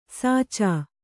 ♪ sācā